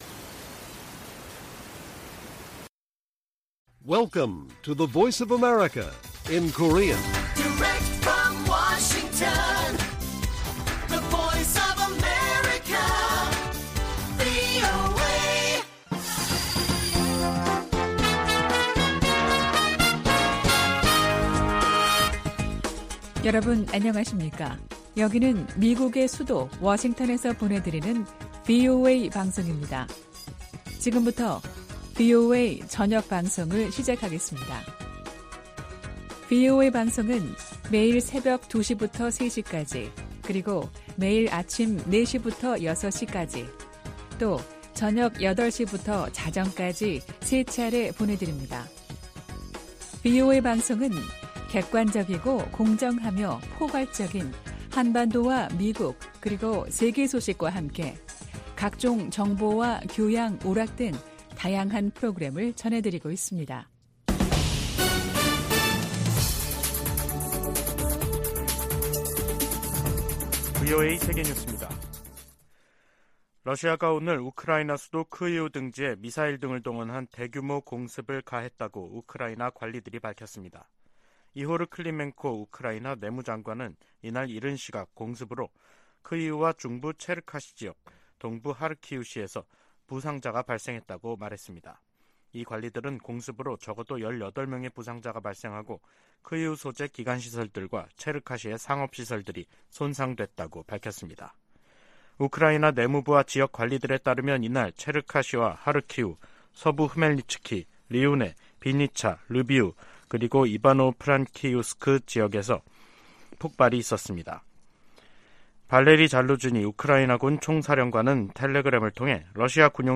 VOA 한국어 간판 뉴스 프로그램 '뉴스 투데이', 2023년 9월 21일 1부 방송입니다. 윤석열 한국 대통령이 유엔총회 연설에서 북한의 핵과 탄도미사일 개발이 세계 평화에 대한 중대한 도전이라고 규탄했습니다.